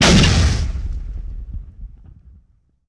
punch3.ogg